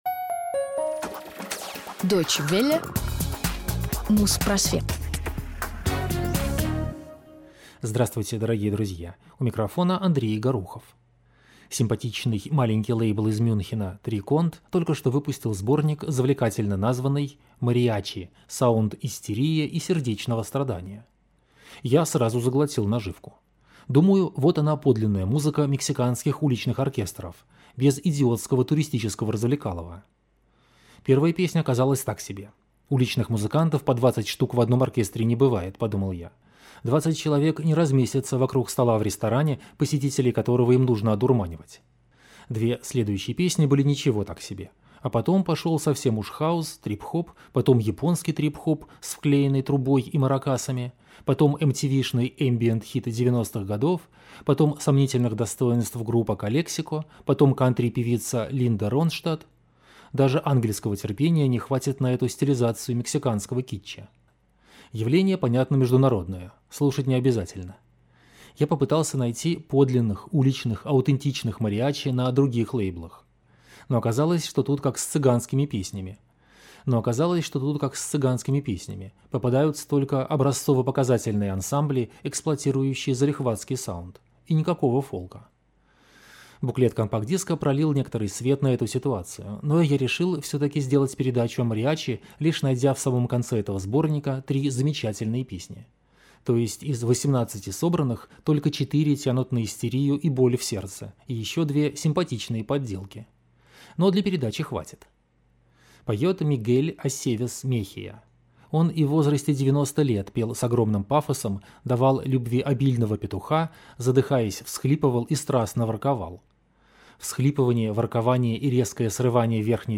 Музпросвет 308 от 5 июля 2008 года - Музыка мексиканских мариачи | Радиоархив